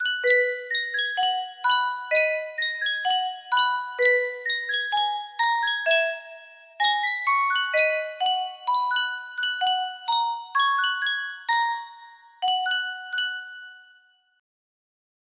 Yunsheng Custom Tune Music Box - Unknown Tune 3 music box melody
Full range 60